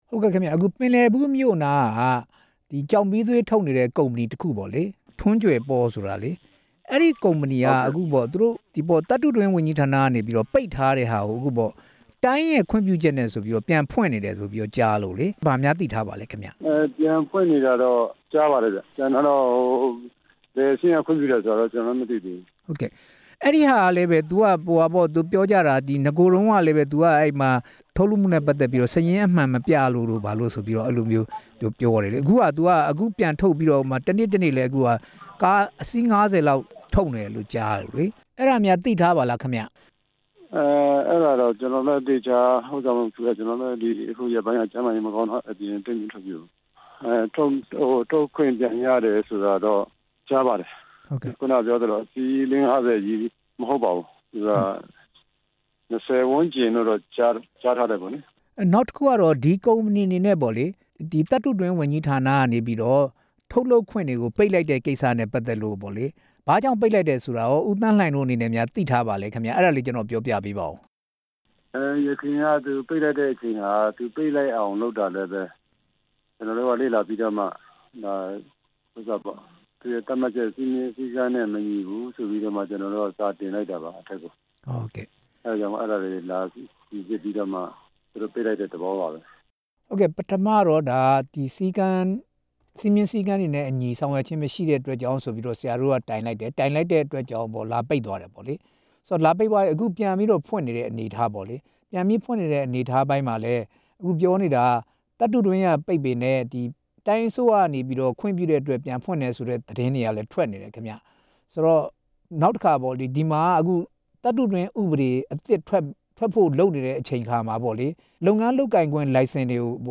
ပင်လယ်ဘူးမြို့အနီးက ကျောက်မီးသွေးတွင်းအကြောင်း မေးမြန်းချက်